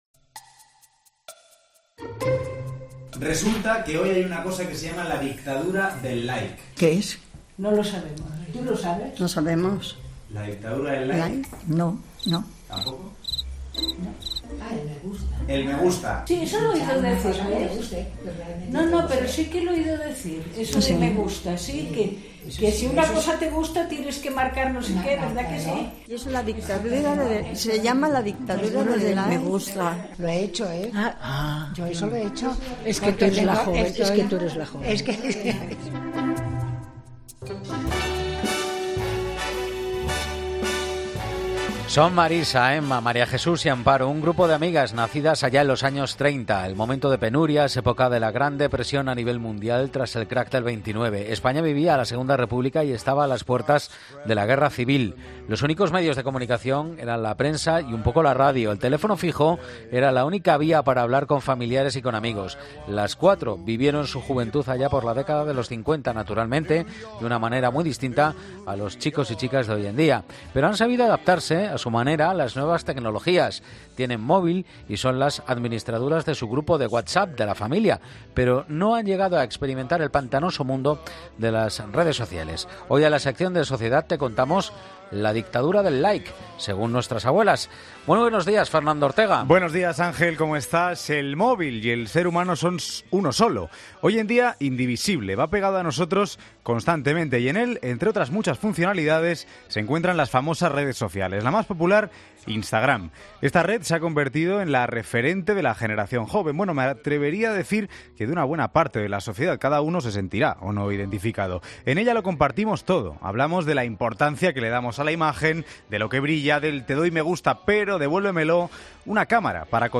Con estas frases y una larga entrevista, el grupo de las “niñas de la guerra” entendió cómo las nuevas generaciones se encuentran bajo lo que muchos expertos denominan la dictadura del like.